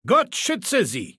Maleold01_ms06_goodbye_000681d2.ogg (OGG-Mediendatei, Dateigröße: 13 KB.
Fallout 3: Audiodialoge